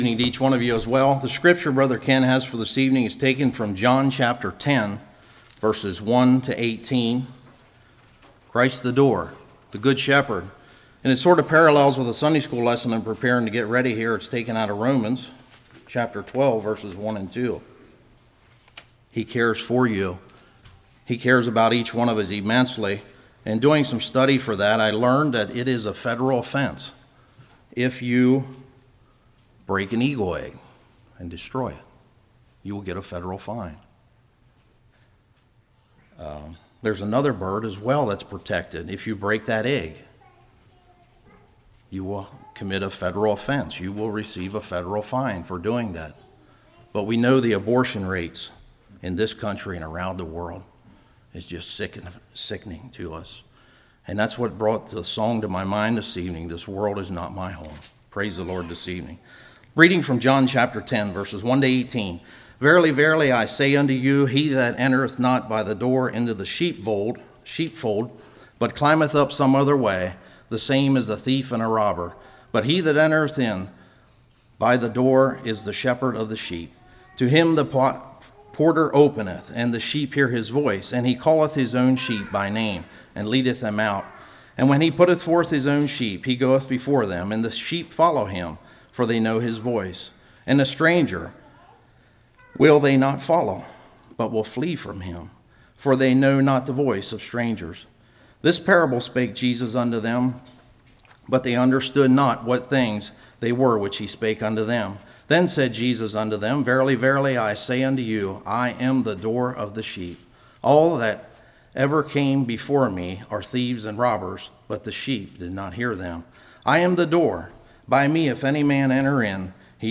Passage: John 10:1-18 Service Type: Evening